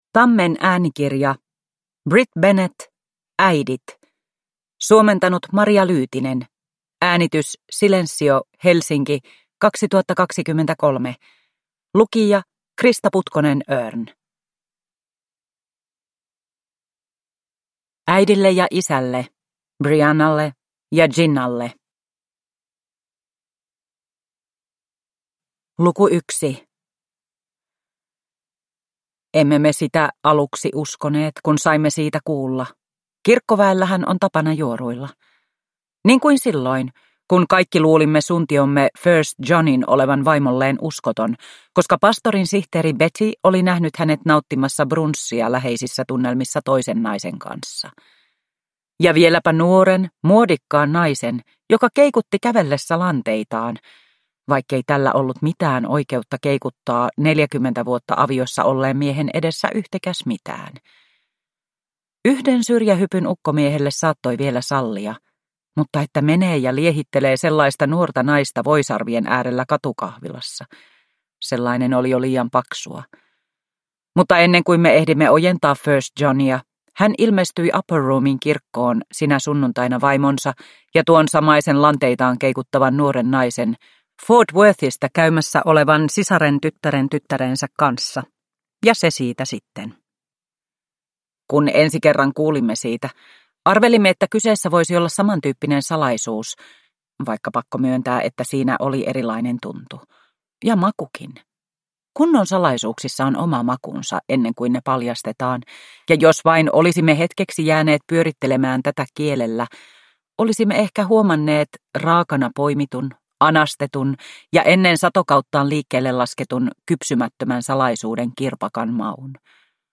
Äidit – Ljudbok – Laddas ner